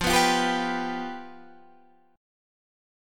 F Suspended 2nd Flat 5th